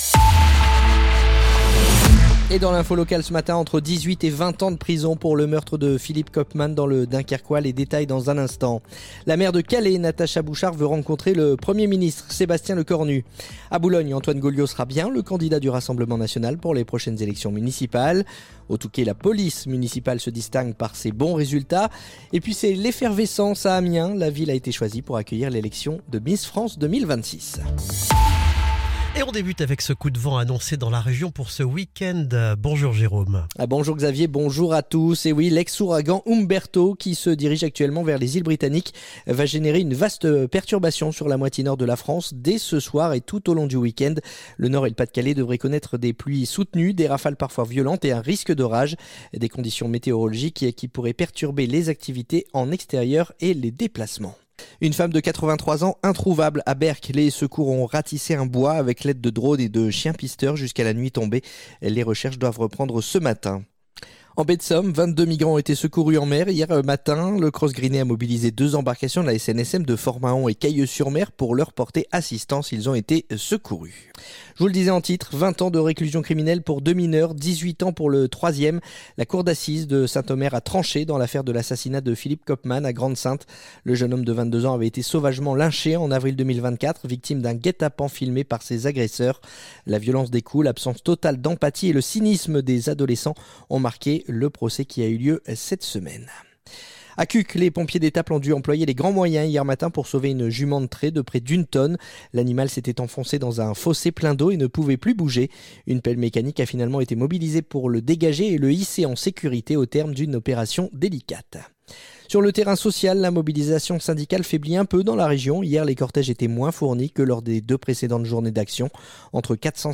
Le journal du vendredi 3 octobre